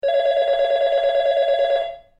House Phone